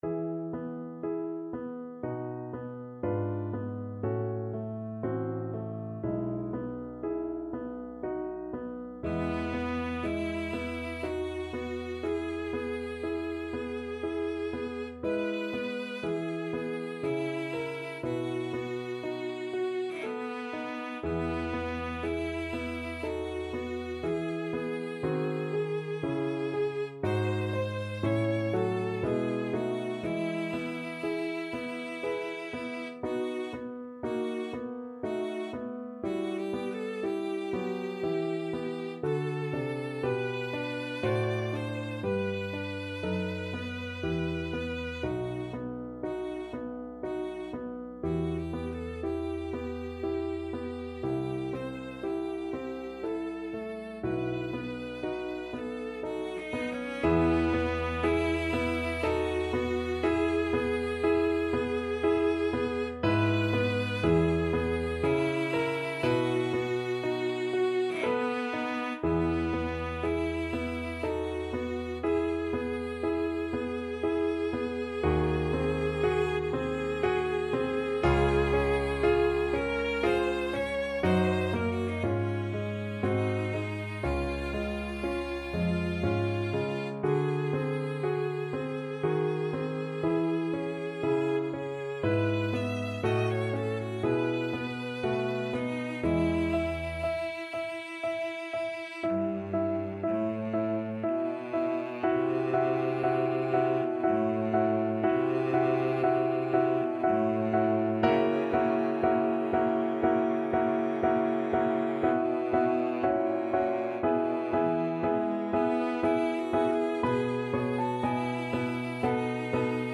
3/4 (View more 3/4 Music)
Adagio
Classical (View more Classical Cello Music)